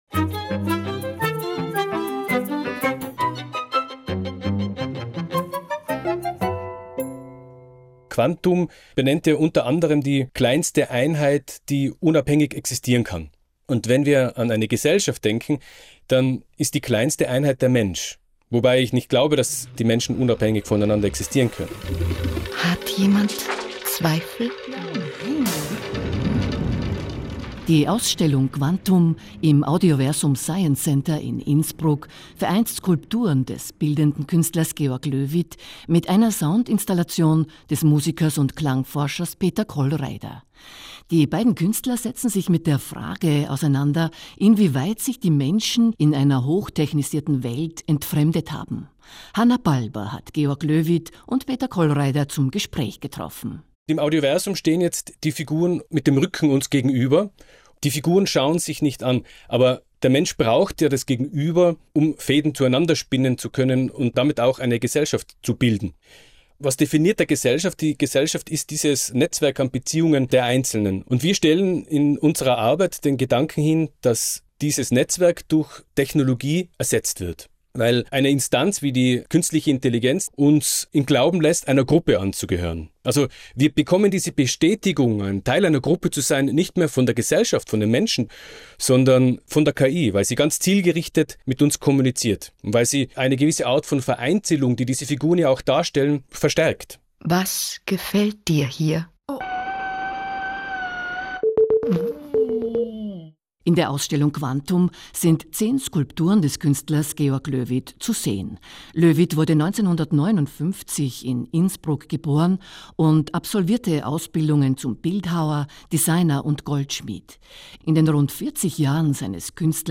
Im Rahmen von stadt_potenziale Innsbruck reiste der „Goldene Stachl“ als mobiles Stadtlabor im Juni 2024 durch die Stadtteile Wilten und Höttinger Au.